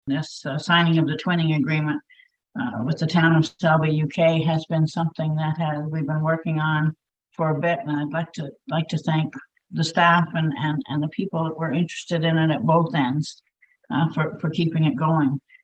Selby, Ontario and Selby in the United Kingdom are now officially twinned following a special meeting featuring the two Selby’s councils.
Napanee Mayor Marg Isbester gave a brief re-cap, for anyone who has not been following along with this story.